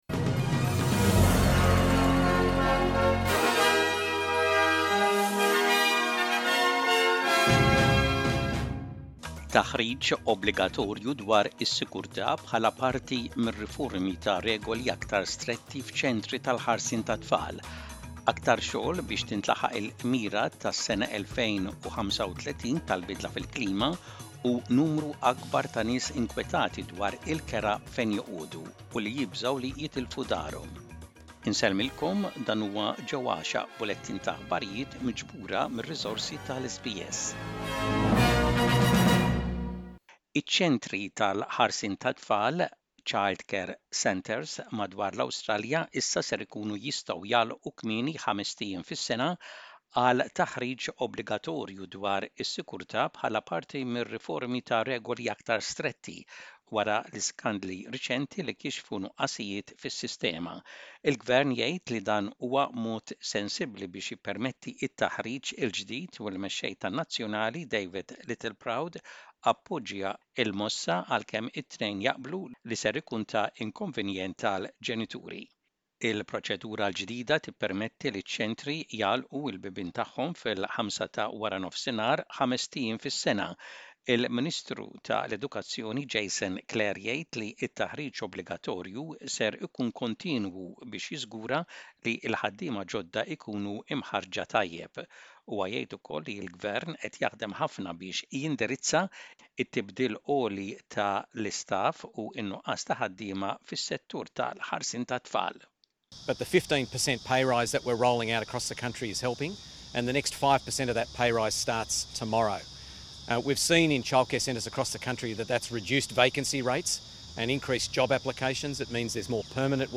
SBS Maltese News - Photo SBS Maltese